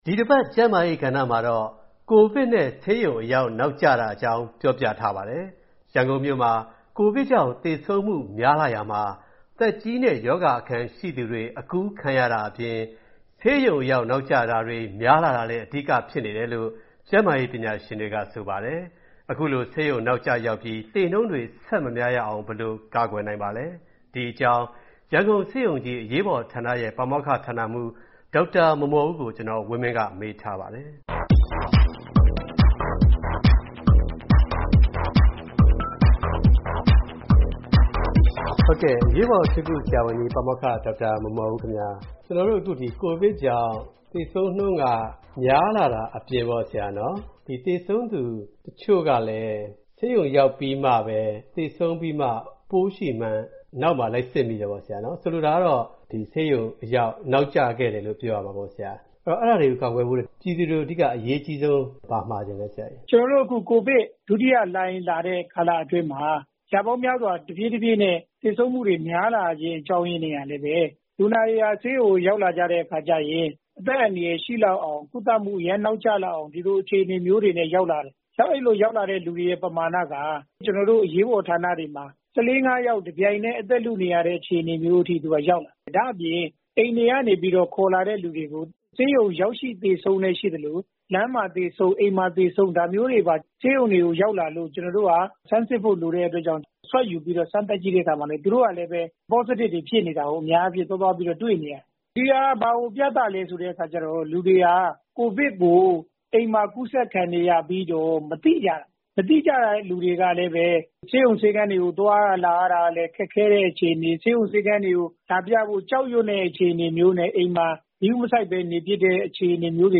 ဆက်သွယ်မေးမြန်းထားပါတယ်